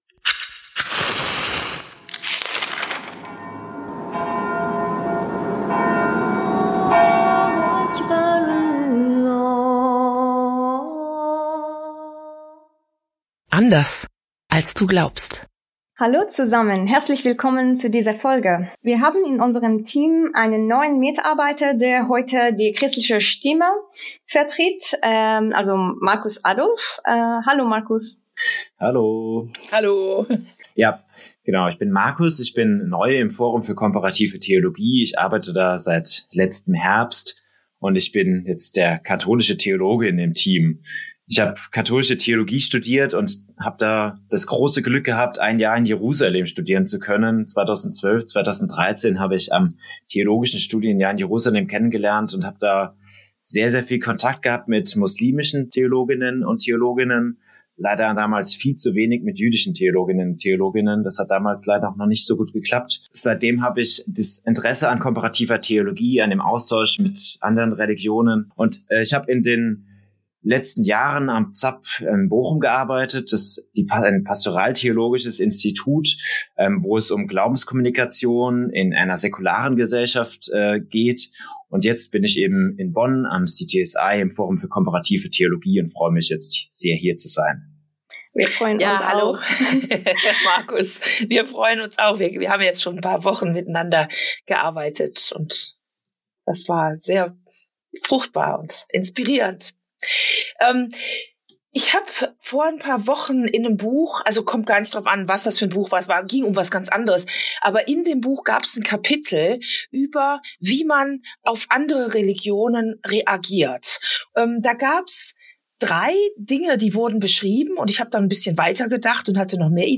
Wie Religionen sich (nicht) begegnen | Gespräch ~ Anders als du glaubst Podcast